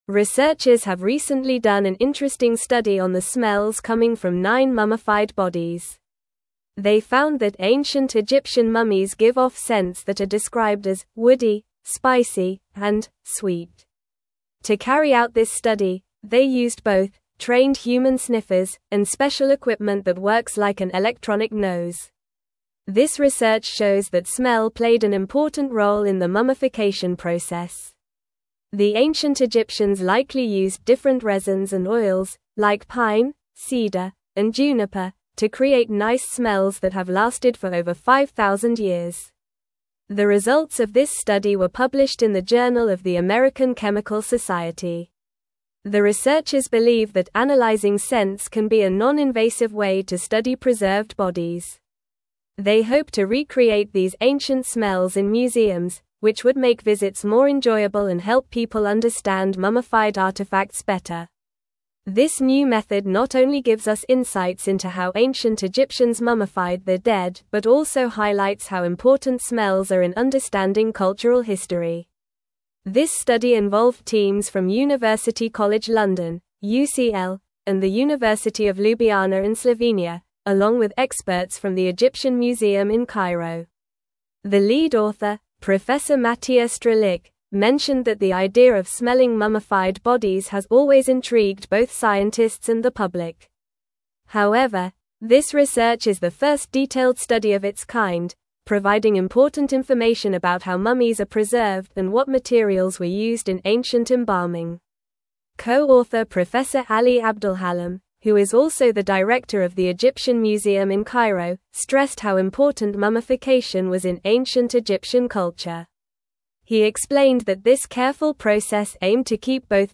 English-Newsroom-Upper-Intermediate-NORMAL-Reading-Ancient-Egyptian-Mummies-Emit-Unique-Scents-Study-Revealed.mp3